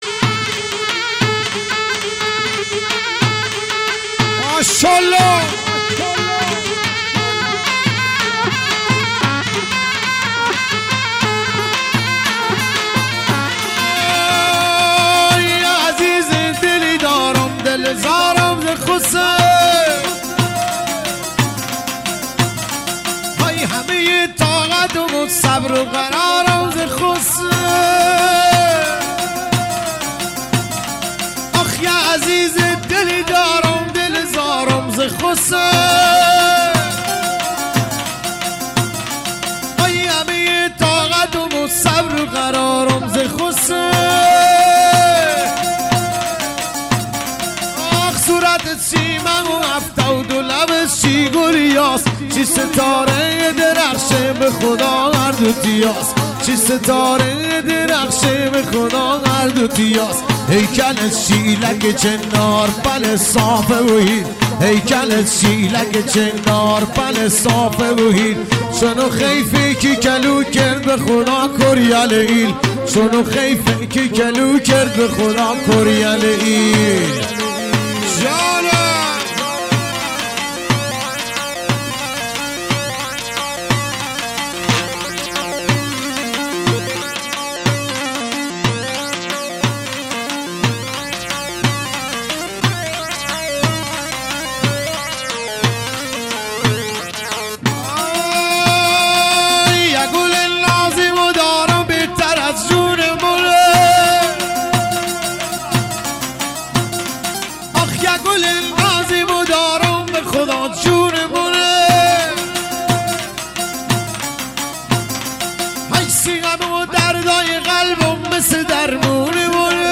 محلی عاشقانه لری